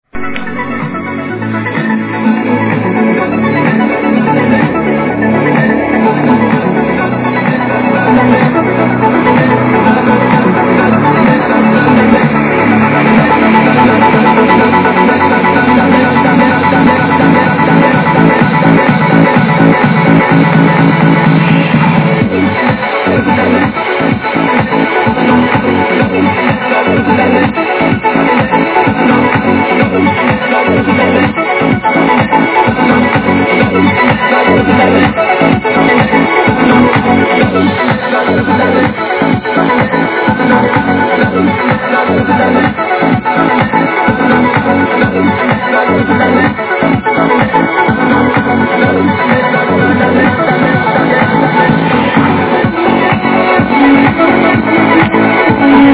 Вот удалось вчера записать трек на диктофон с радио.